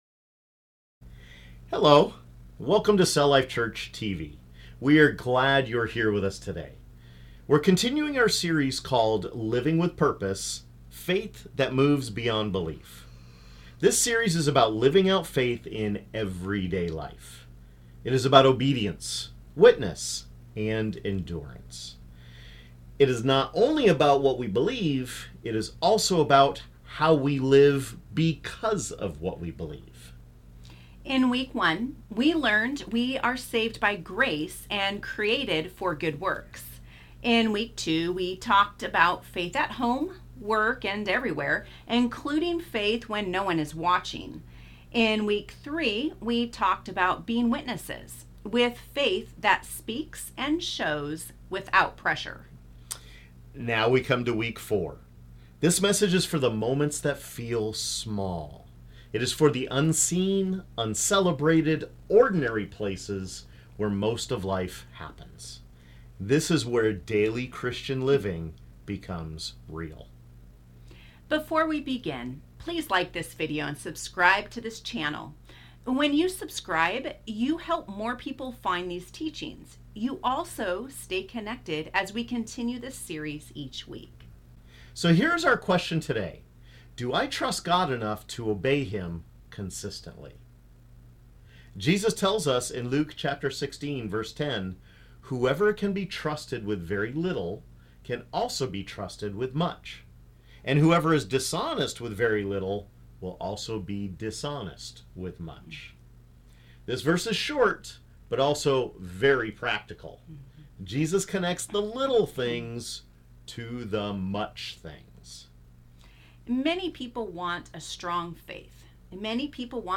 Luke 16:10 shows that daily Christian living is shaped through small, faithful acts of obedience to Christ. In this Week 4 teaching, we focus on faithfulness in unseen, ordinary moments and how God uses the little things to form a steady life.